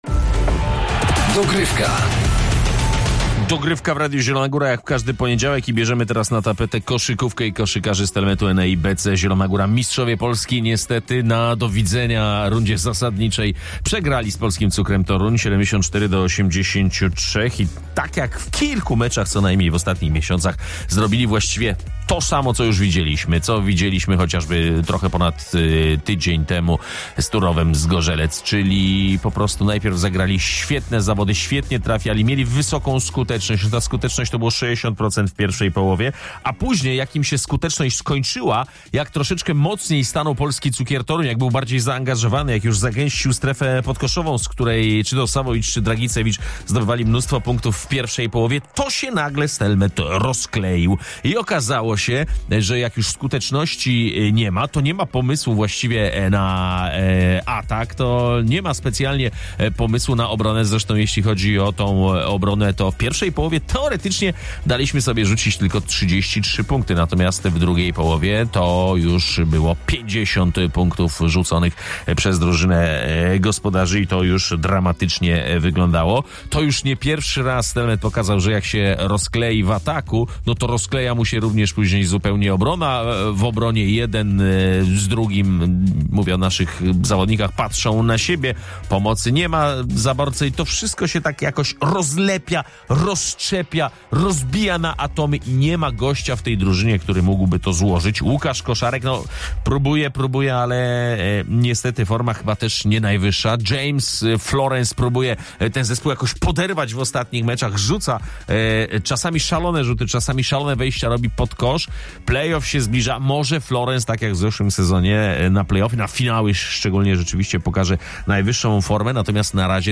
O formie Stelmetu rozmawiamy więc z zawodnikami naszej ekipy, która przegrała w niedzielę z Polskim Cukrem Toruń choć zaczęła mecz genialnie.